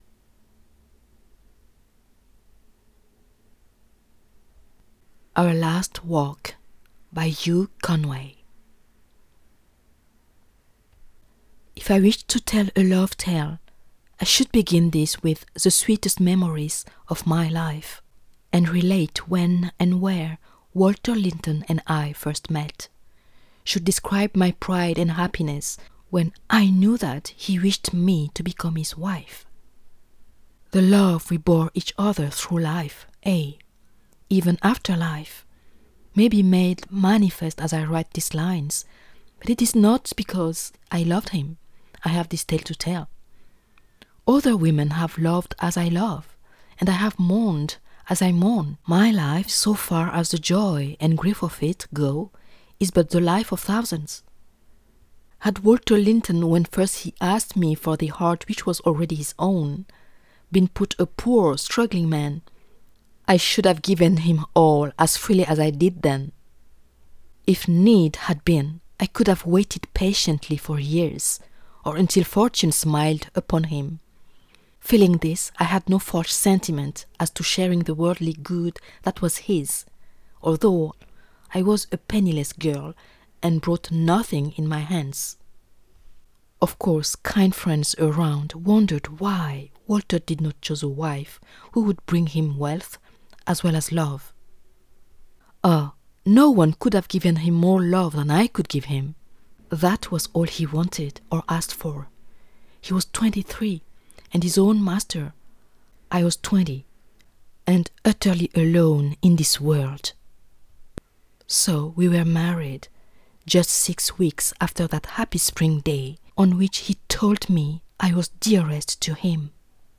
Audio Book Samples
Fiction Romance (English)
Poignant, Emotional, Introspective
OurLastWalk_Fiction-Romance_Demo.mp3